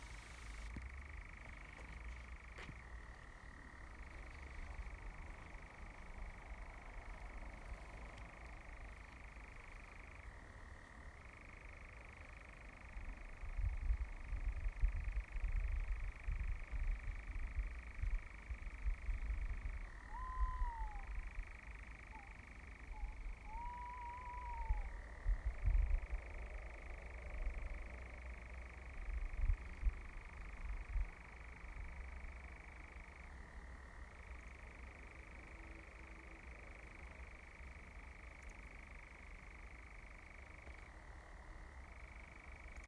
Nightjar and Tawny Owl
The Forest of Dean